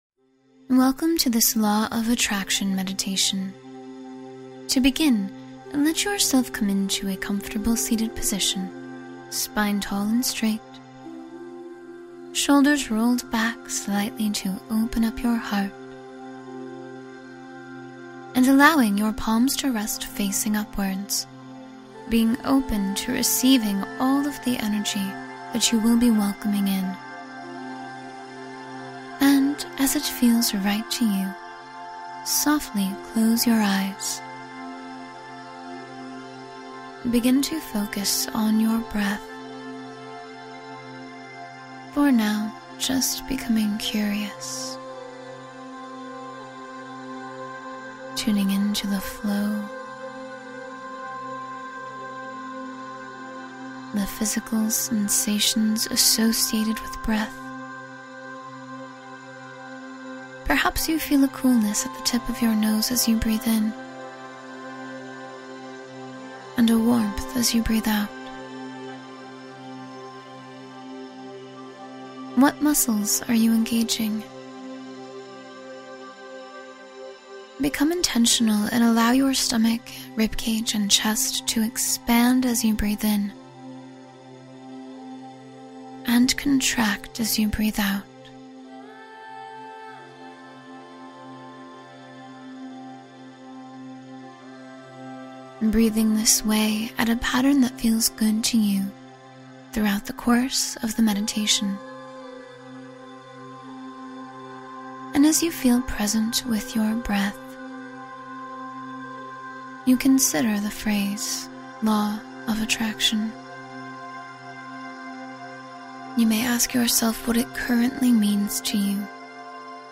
Manifest Joy and Alignment — Guided Meditation for a Radiant Day